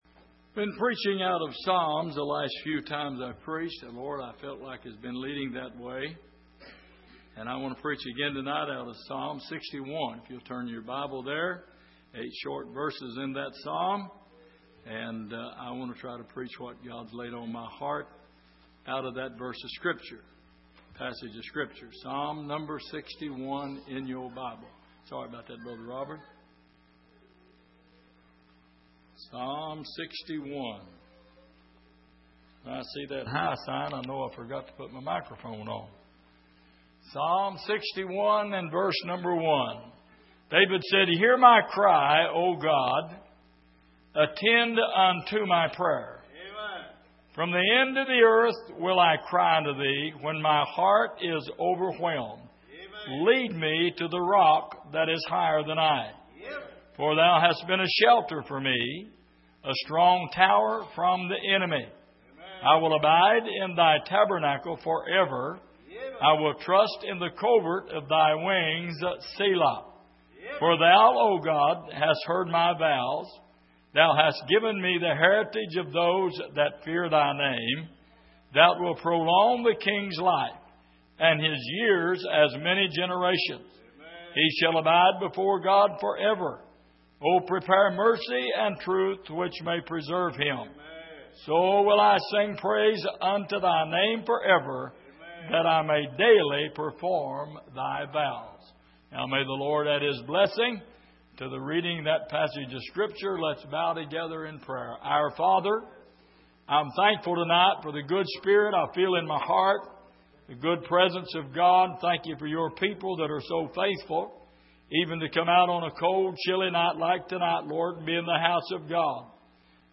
Passage: Psalm 61:1-8 Service: Midweek